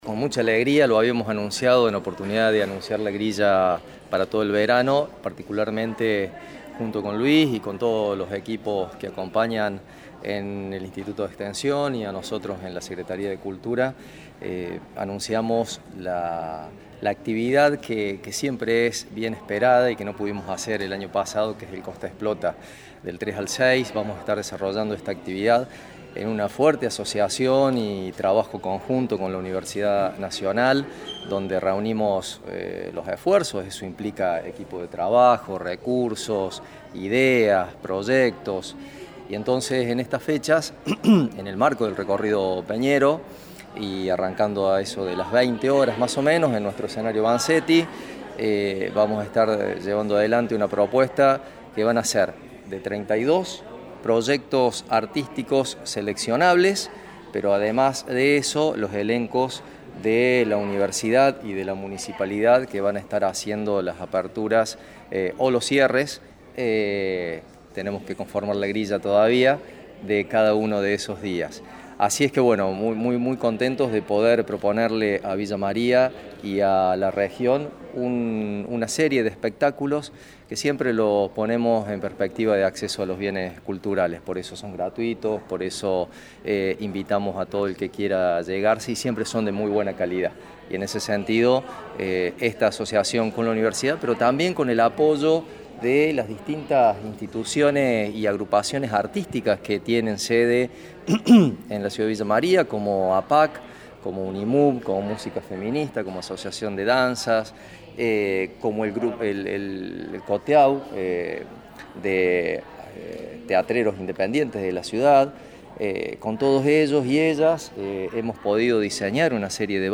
El secretario de Educación, Cultura y Promoción de la Ciencia, Rafael Sachetto, habló con los medios y brindó detalles.